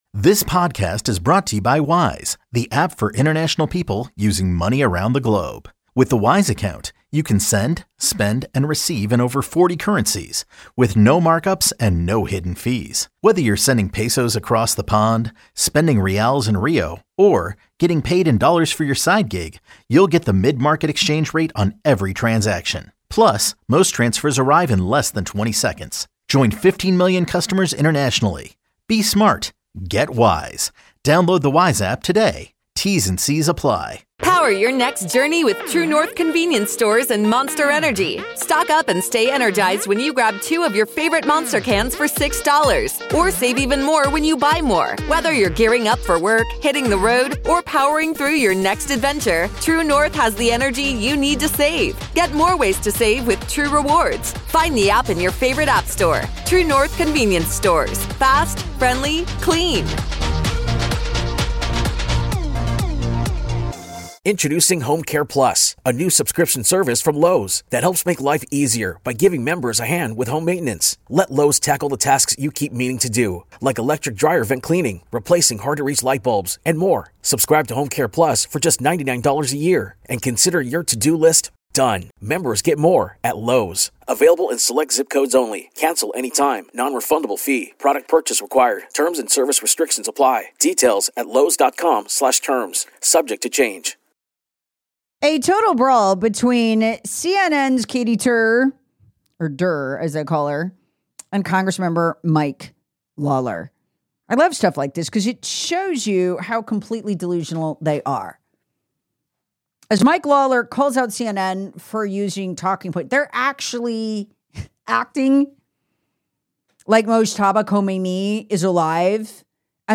A cable news interview spirals into a full-blown political brawl as Rep. Mike Lawler calls out CNN and anchor Katie Tur over Iran coverage, intelligence claims, and battlefield narratives—while accusing media of repeating “talking points” and ignoring military developments.
The exchange escalates as both talk over each other, with Lawler accusing the media of distorting reality and Tur defending journalistic sourcing and intelligence consensus.
Exchange devolves into interruptions, accusations, and overlapping dialogue